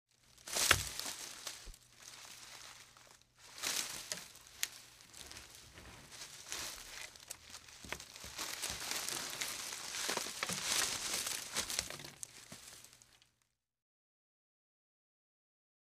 BrushTwigsRustling FS057601
Brush Twigs Rustling; Light And Steady Brush And Leaves Rustling. - Rusting Brush